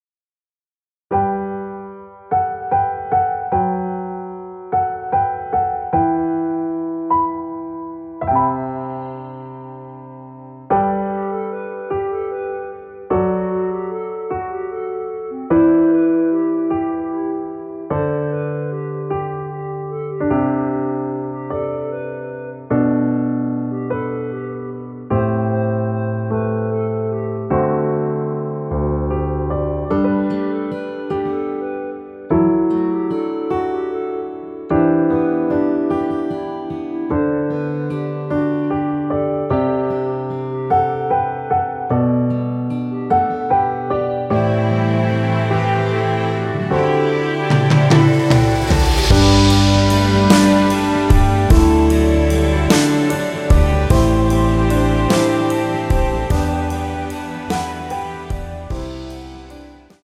+2)올린(짧은편곡)멜로디 MR입니다.
앞부분30초, 뒷부분30초씩 편집해서 올려 드리고 있습니다.
중간에 음이 끈어지고 다시 나오는 이유는
곡명 옆 (-1)은 반음 내림, (+1)은 반음 올림 입니다.
(멜로디 MR)은 가이드 멜로디가 포함된 MR 입니다.